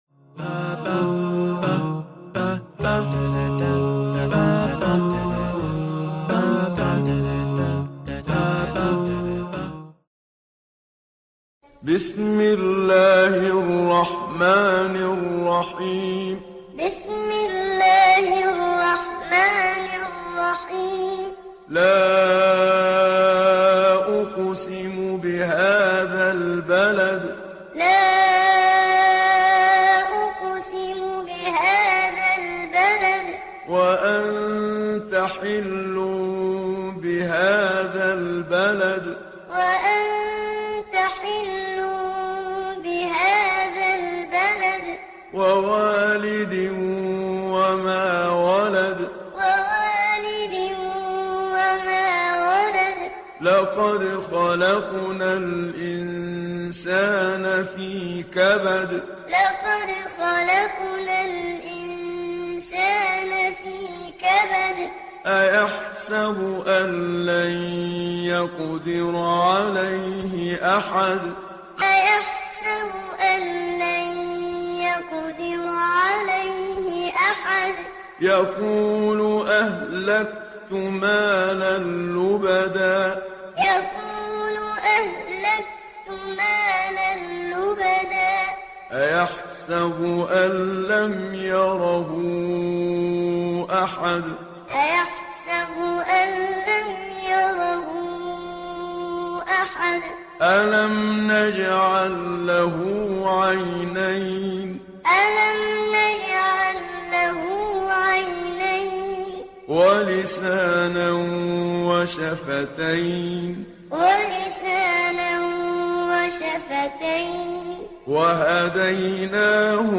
090 - Al-Balad - Qur'an Time - Read Along.mp3